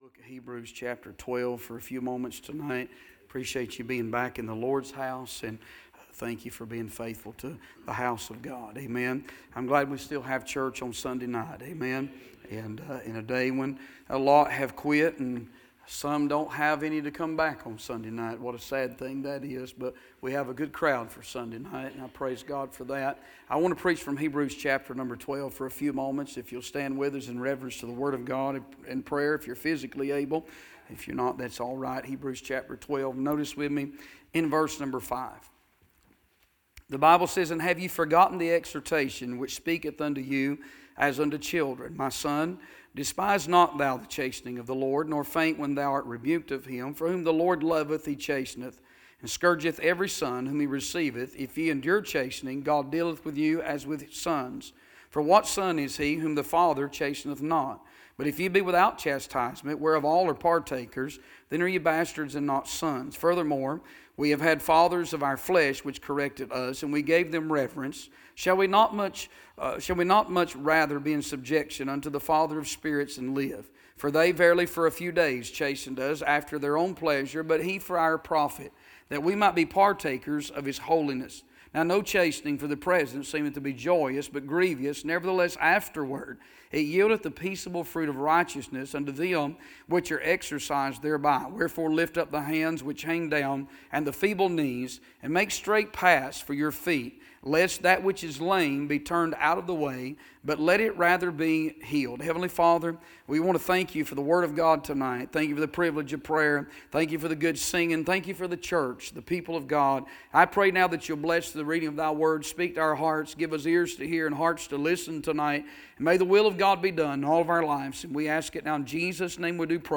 Sermons - Bible Baptist Church